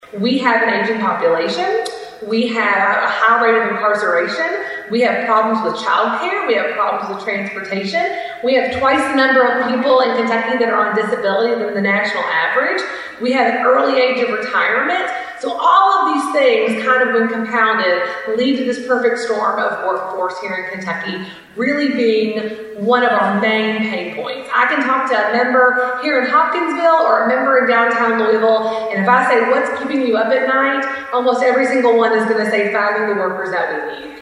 Thursday morning’s Christian County Chamber of Commerce Legislative Eye Opener Breakfast at the Silo Events Center brought one significant topic into focus.